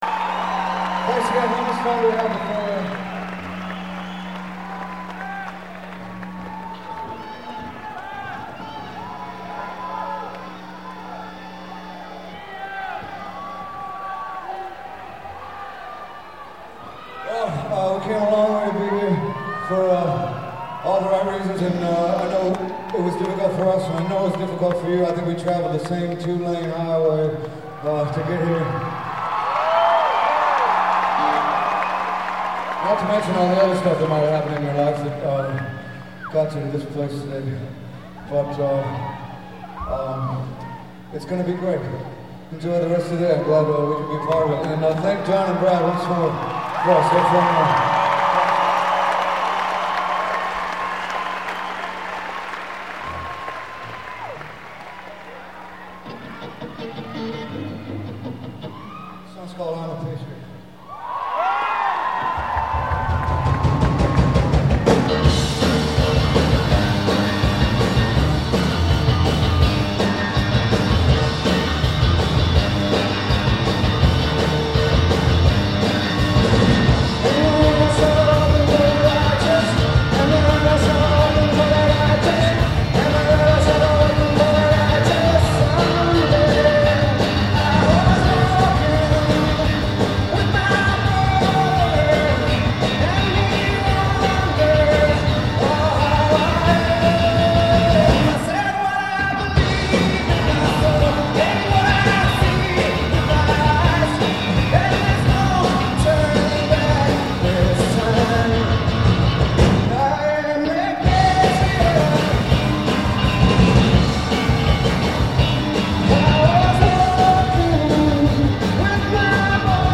live from 6/13/99